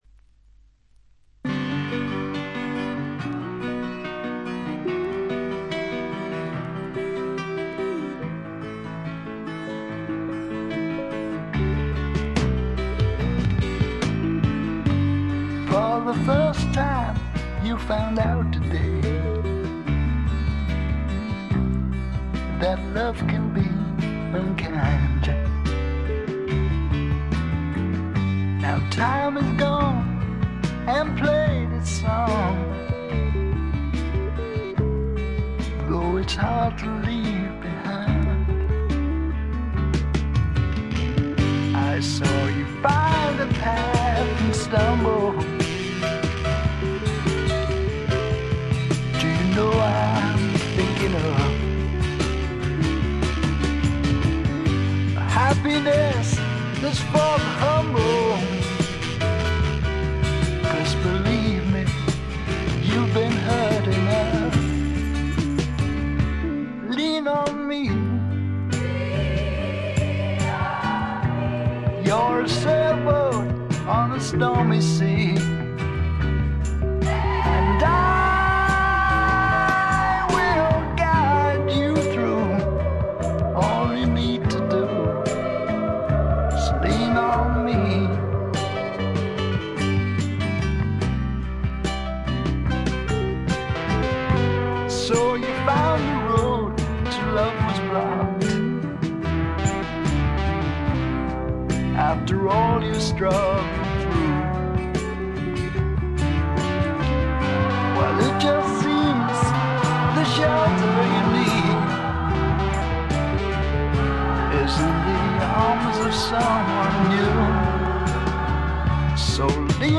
わずかなノイズ感のみ。
典型的かつ最良の霧の英国、哀愁の英国スワンプ路線の音作りで、端的に言って「アンドウェラしまくり」です。
搾り出すような激渋のヴォーカルがスワンプ・サウンドにばっちりはまってたまりません。
試聴曲は現品からの取り込み音源です。